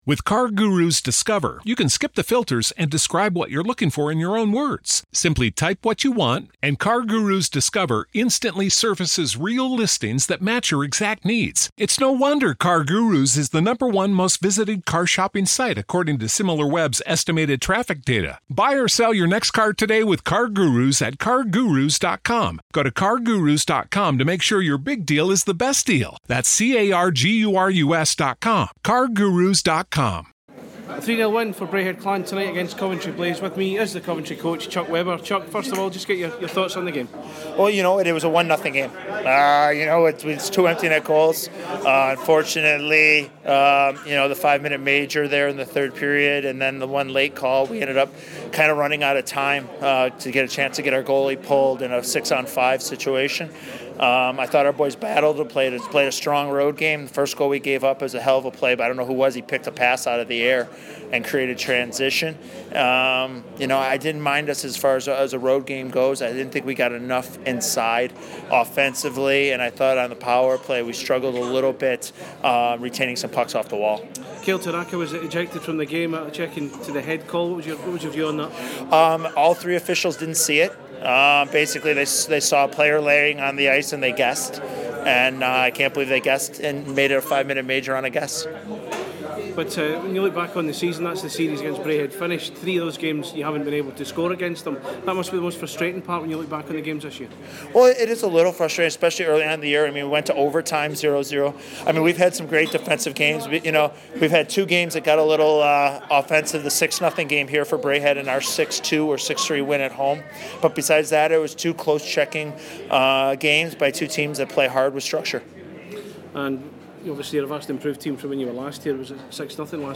POST MATCH